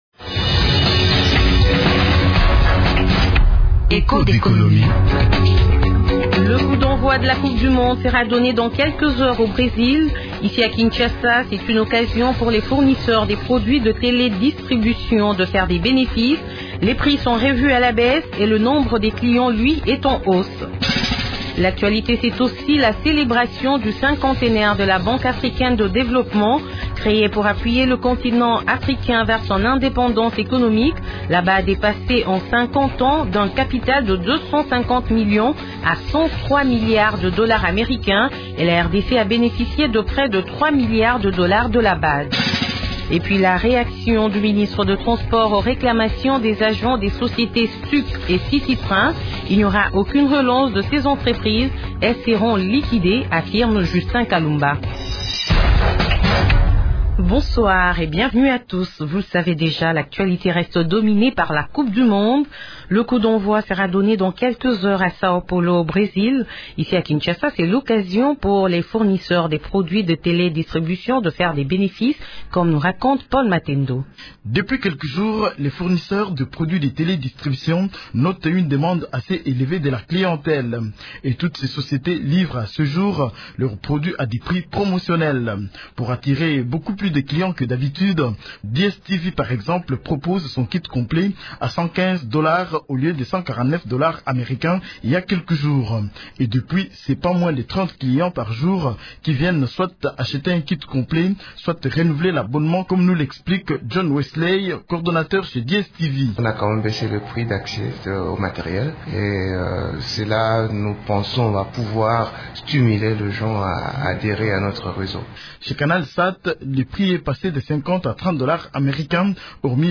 Engouement devant les sièges de Canal Sat, DS TV et Startimes…, reportage à suivre dans l’émission Echos d’économie. Il est également question dans ce magazine de la liquidation de Stuc et City Train.